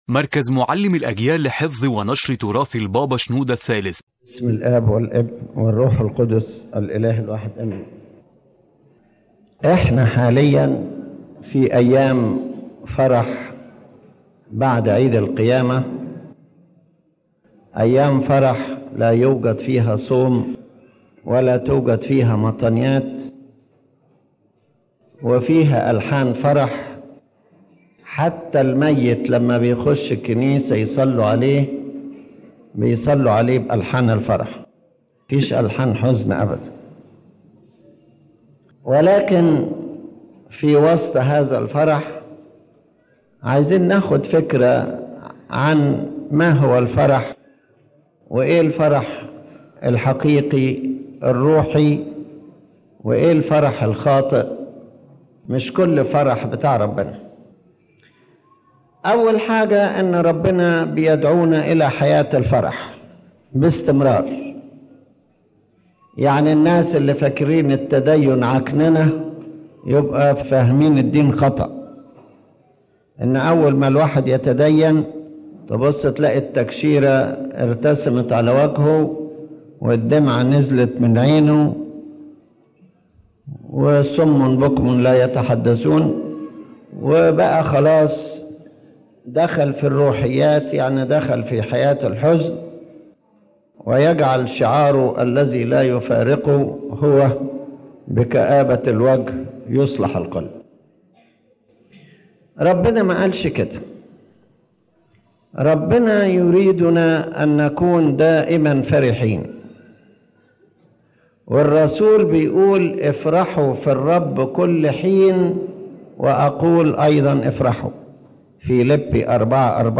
The lecture reminds that Christianity is not a life of sorrow but an invitation to true continuous joy “in the Lord.”